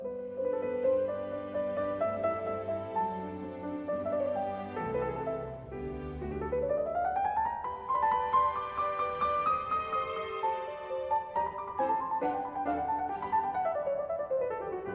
Orchestre : 1 flûte, 2 hautbois, 2 bassons, 2 cors en Ut, 2 trompettes en Ut, 2 timbales (Ut et Sol), quatuor.
Exposé au piano, en Sol Majeur (Dominante). Un pur chef-d'oeuvre de naturel et de légèreté de l'harmonie.